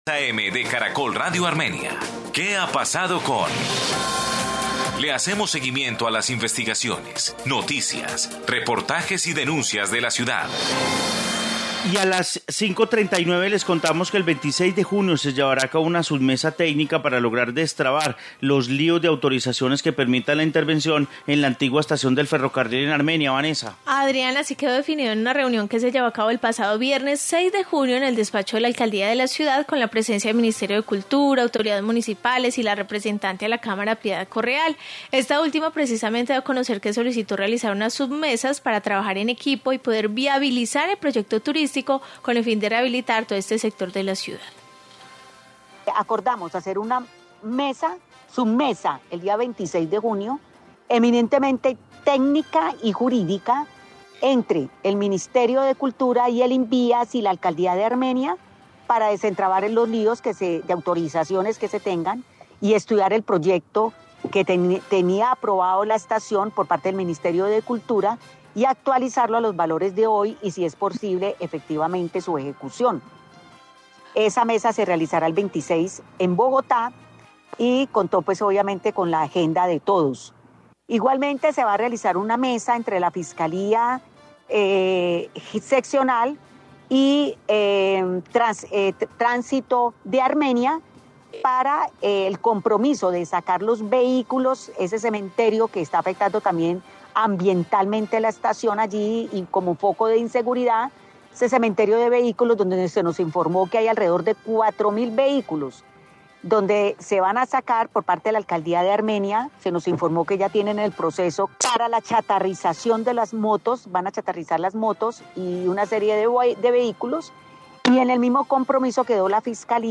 Informe sobre reunión